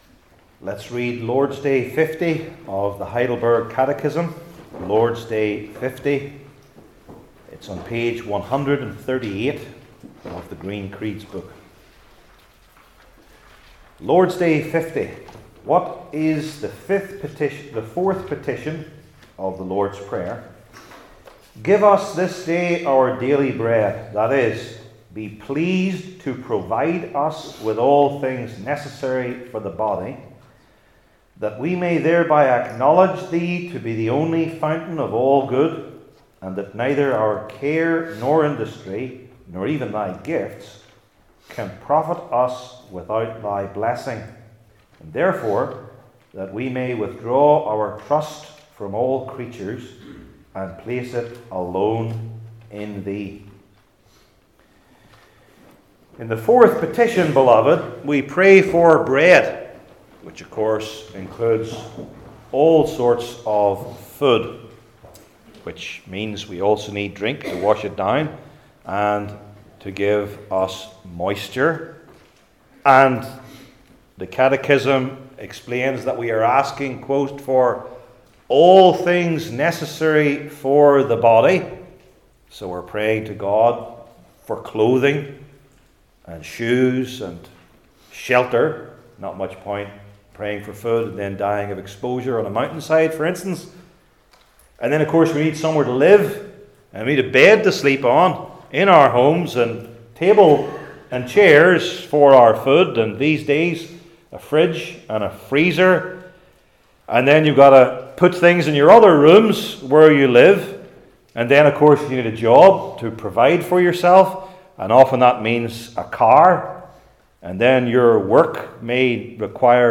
The Lord's Prayer Passage: I Chronicles 29:6-19 Service Type: Heidelberg Catechism Sermons I. When Poor II.